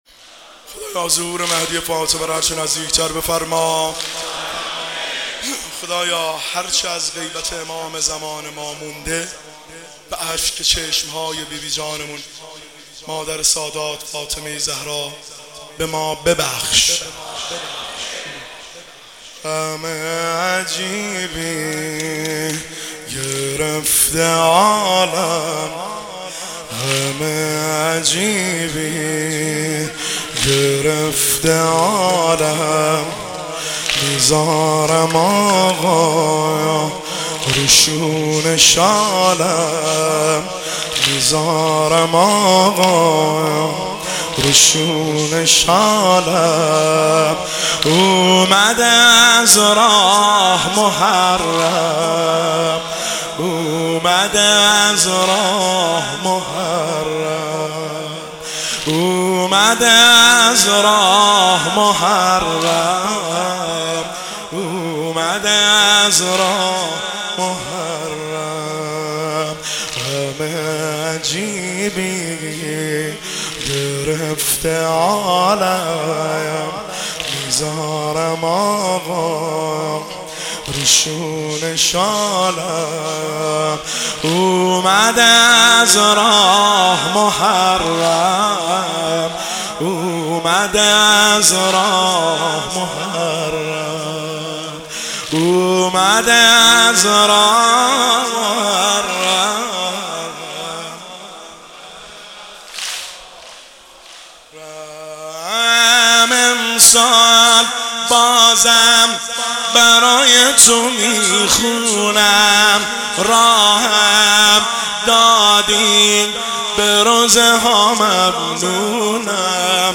سنگین  شب سوم محرم الحرام 1404
هیئت خادم الرضا قم